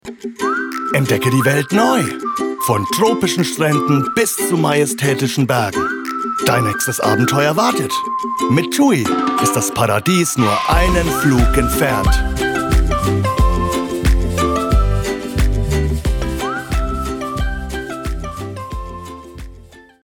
Werbung: „Dein Traumurlaub“